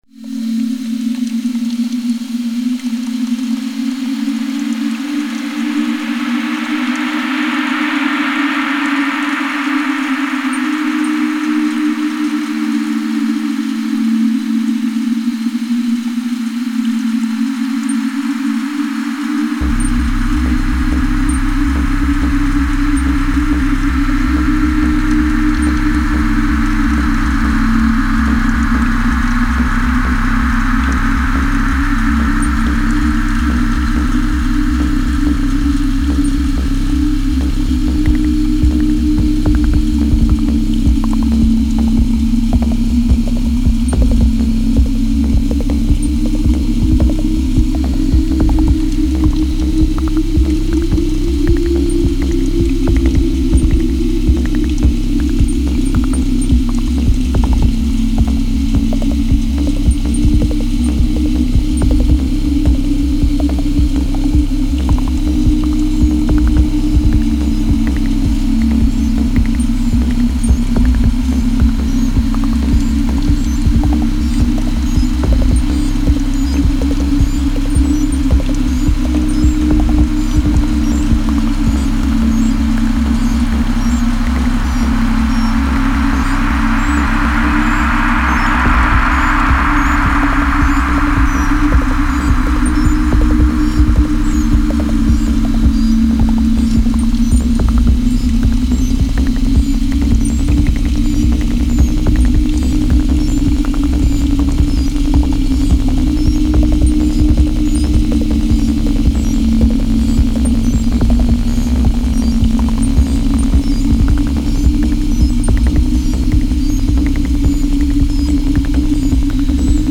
the original idea was to do simple sine waves dissonance but somehow I managed to fuck it up and complicate thing, as usual…